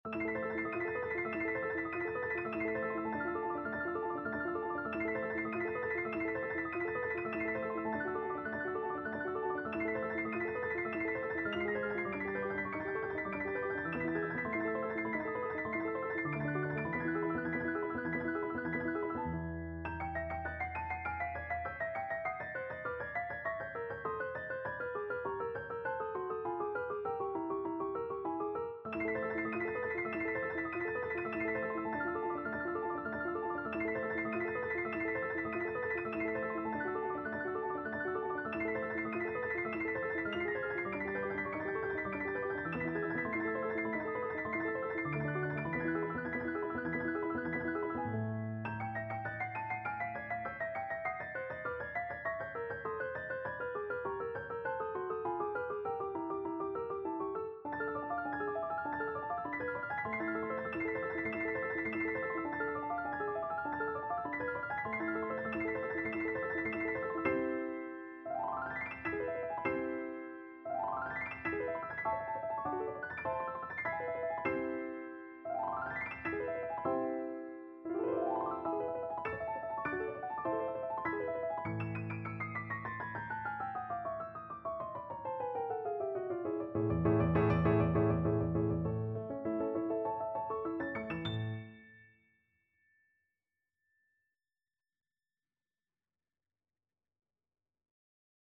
Piano version
No parts available for this pieces as it is for solo piano.
4/4 (View more 4/4 Music)
Andantino (View more music marked Andantino)
Piano  (View more Advanced Piano Music)
Classical (View more Classical Piano Music)